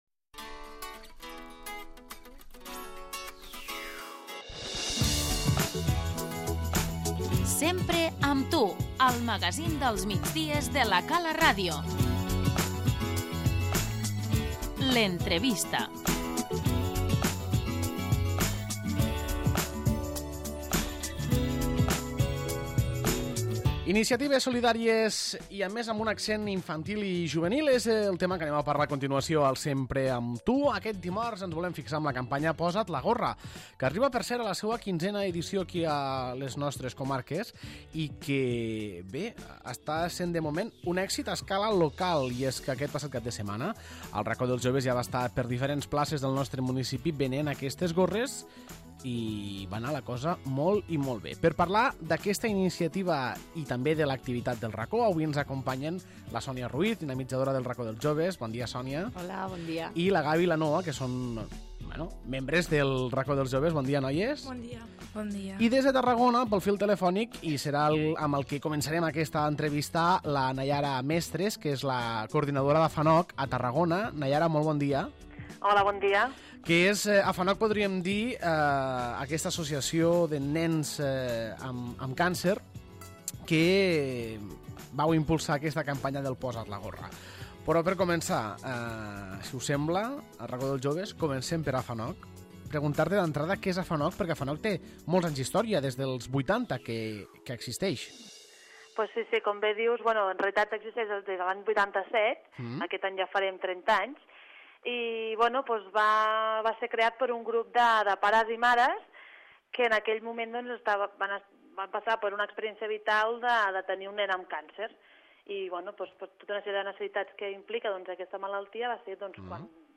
L'entrevista - 'Posa't la gorra!' amb AFANOC i el Racó dels Joves